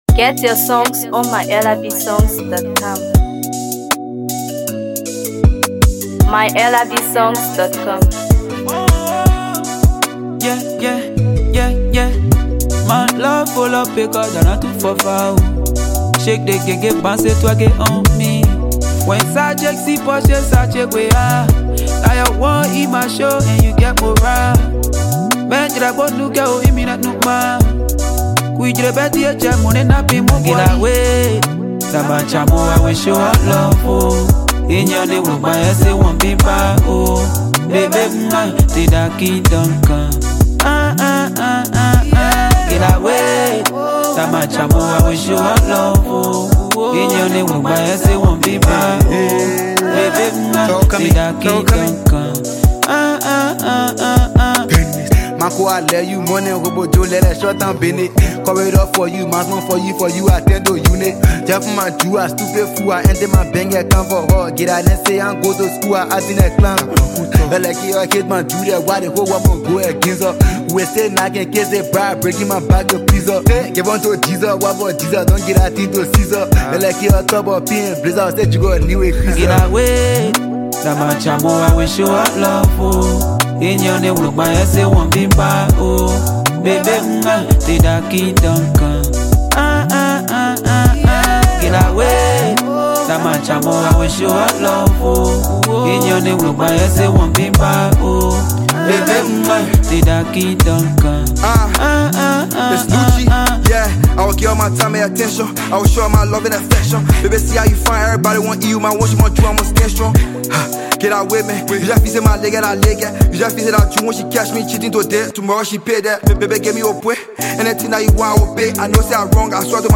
Afro PopMusic
a smooth and emotionally charged love song
a feel-good Afro-inspired tune